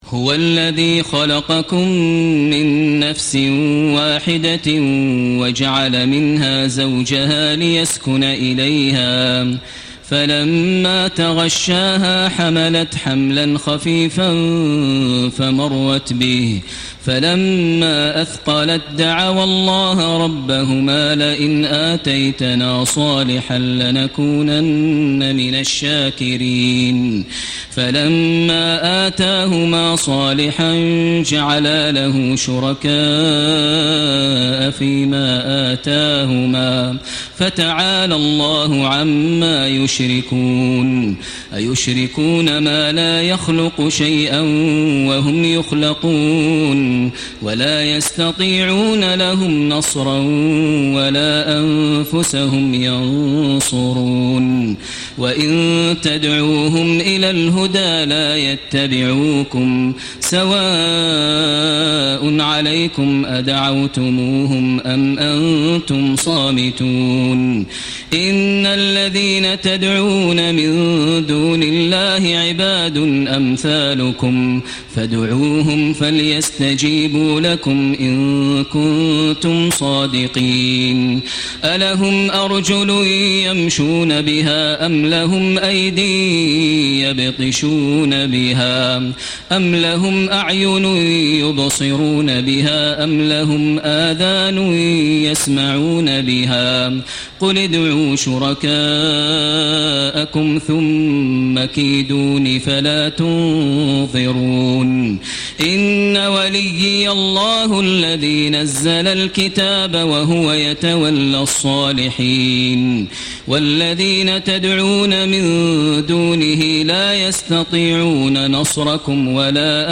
تهجد ليلة 29 رمضان 1434هـ من سورتي الأعراف (189-206) و الأنفال (1-40) Tahajjud 29 st night Ramadan 1434H from Surah Al-A’raf and Al-Anfal > تراويح الحرم المكي عام 1434 🕋 > التراويح - تلاوات الحرمين